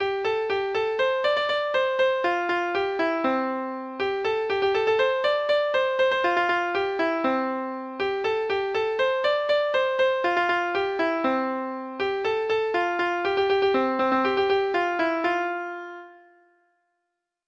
Treble Clef Instrument version
Folk Songs